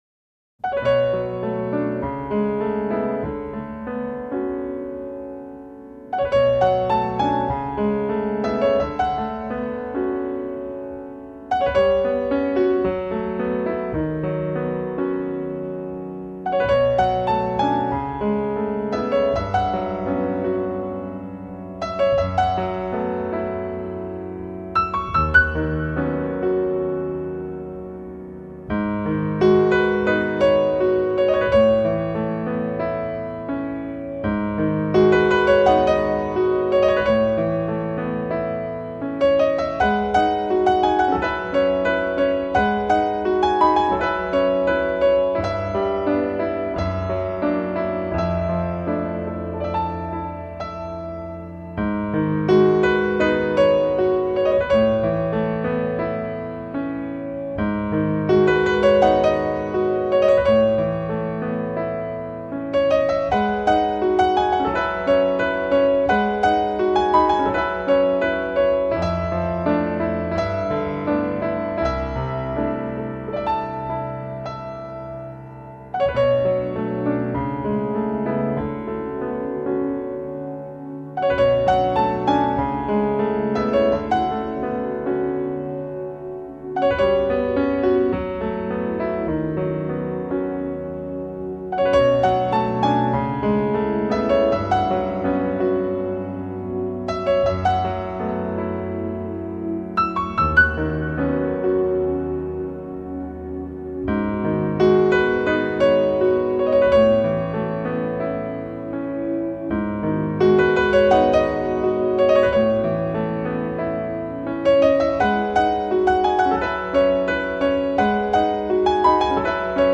合成管弦乐，电脑创作鼓点，重低音，钢琴的升调。